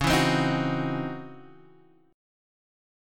C#mM11 chord